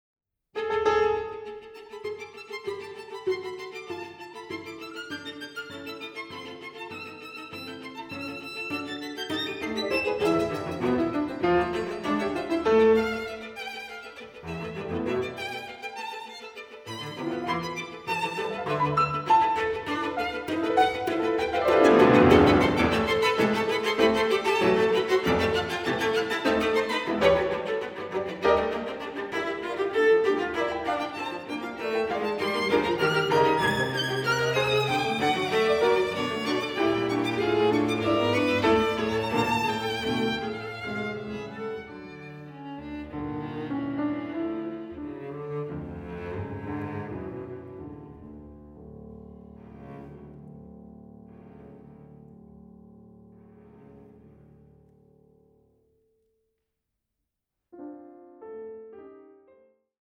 IV. Molto vivace